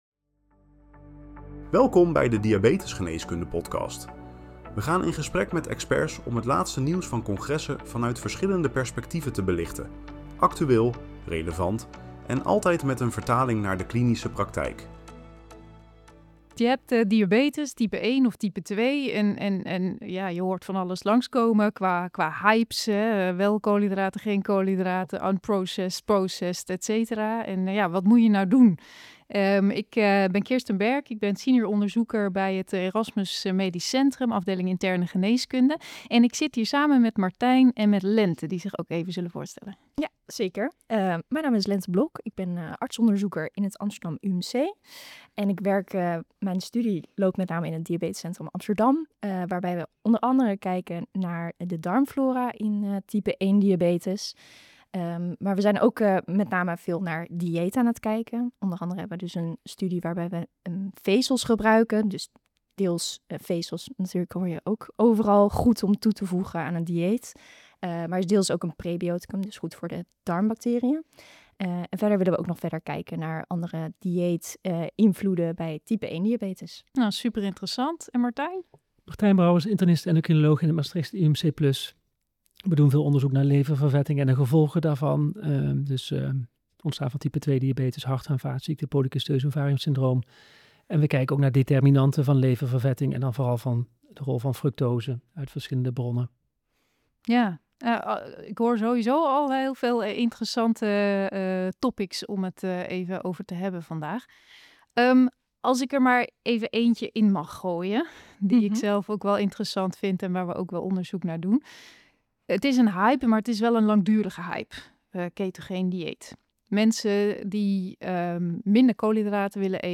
In deze aflevering van de Diabetesgeneeskunde Podcast bespreken drie experts de huidige inzichten rondom voedingsinterventies bij type 1 en type 2 diabetes. We gaan in op de fysiologische en metabole effecten van koolhydraatbeperking, de rol van voedingsvezels in glykemische variabiliteit en tijd-in-bereik, en de mogelijke impact van darmmicrobioom-modulatie op ontsteking en metabole uitkomsten. Daarnaast komt de invloed van ultra-bewerkte voeding aan bod.